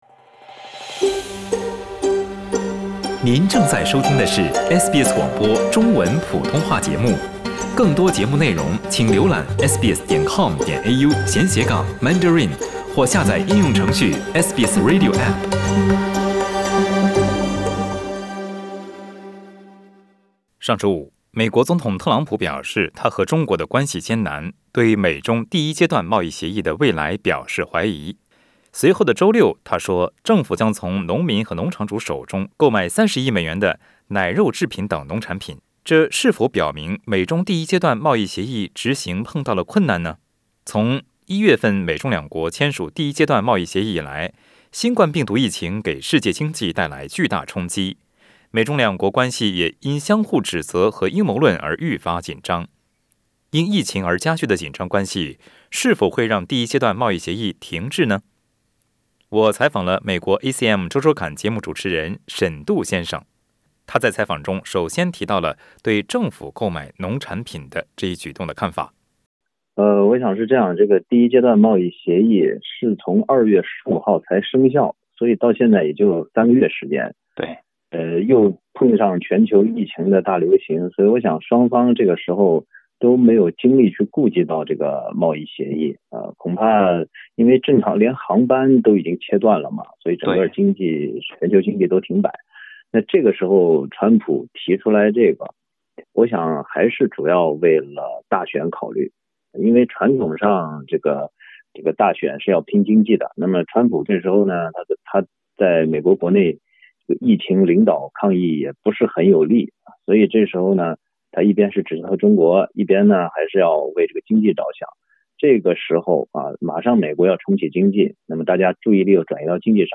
因疫情而加剧的紧张关系是否会让第一阶段贸易协议停滞不前？点击上图收听录音采访。